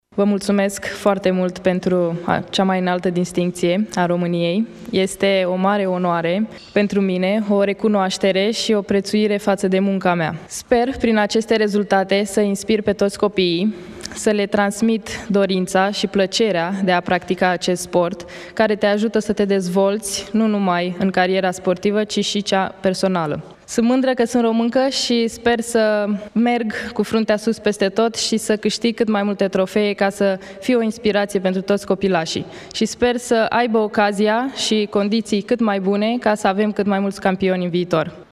Simona Halep, primul român care câştigă turneul de tenis de la Wimbledon a fost decorată în urmă cu puțin timp la Palatul Cotroceni de preşedintele Klaus Iohannis cu Ordinul Naţional ”Steaua României” în grad de cavaler.
Vizibil emoționată, Simona Halep a declarat că succesul său se datorează familiei, antrenorilor și românilor de pretutindeni care o susțin: